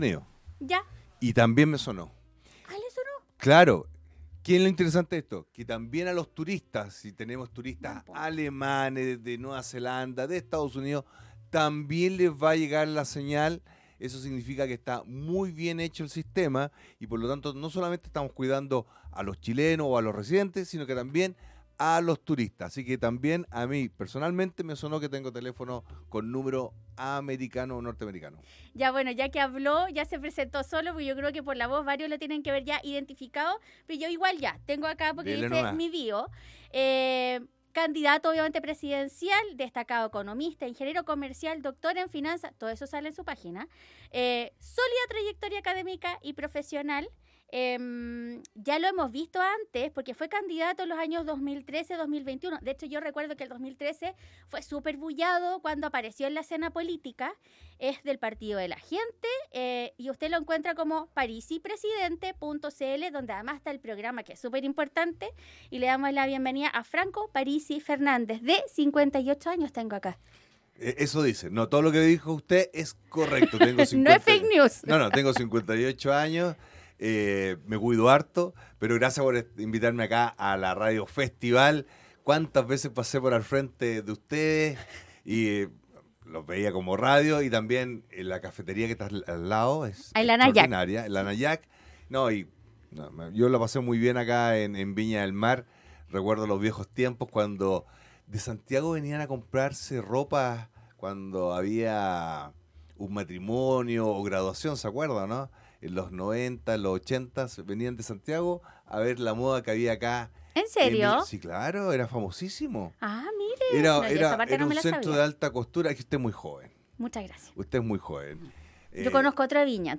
Candidato Presidencial Franco Parisi en los estudios de Radio Festival